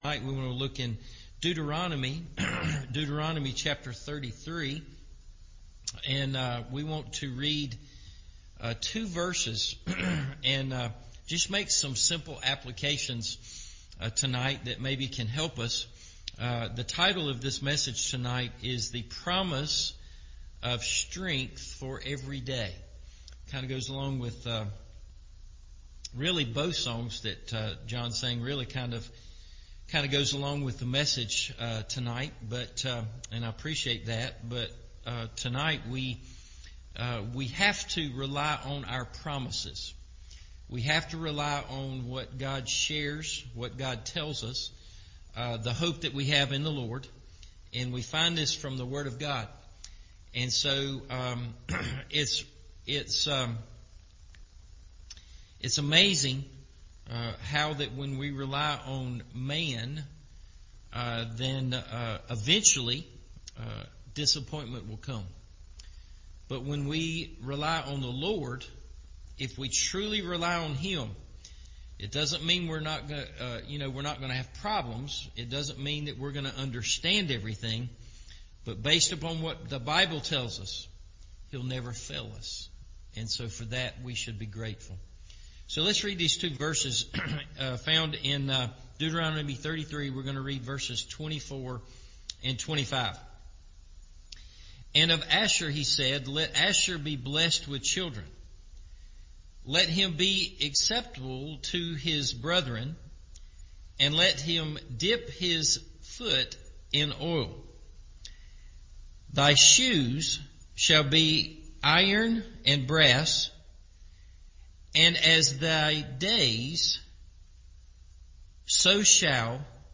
The Promise Of Strength For Every Day – Evening Service – Smith Grove Baptist Church